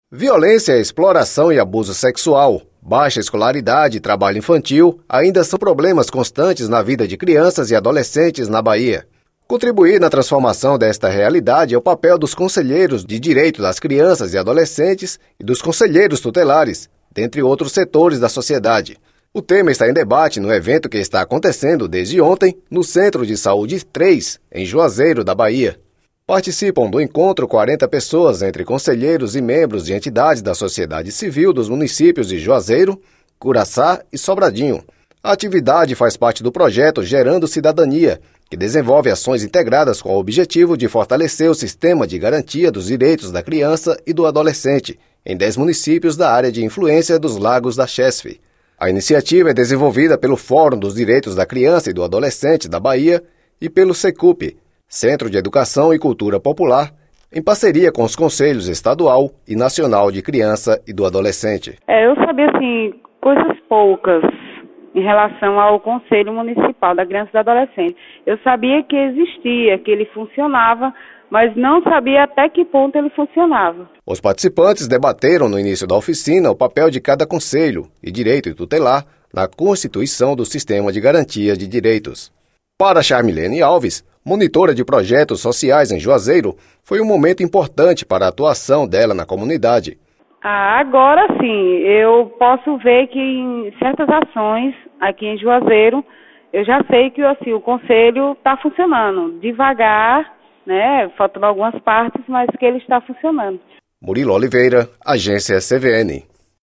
reportagem de áudio distribuída para mais de 100 rádios da Bahia